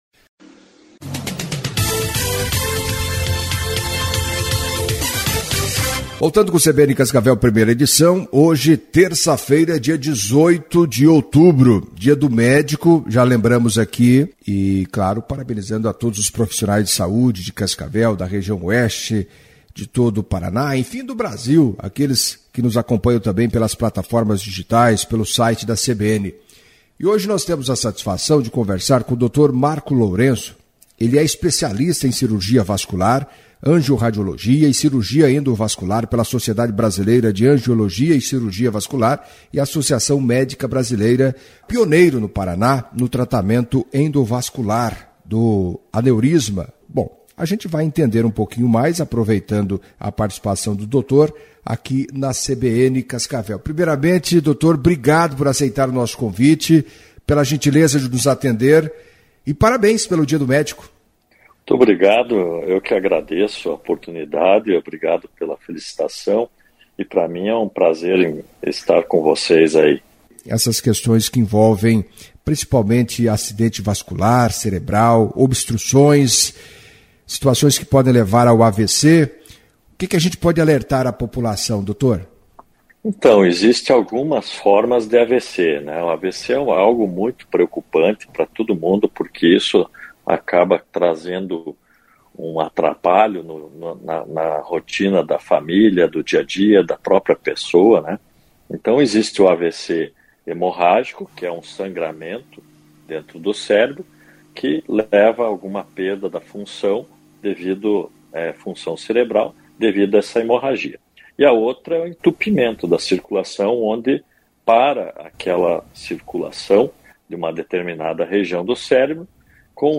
Em entrevista à CBN Cascavel nesta terça-feira (18), o médico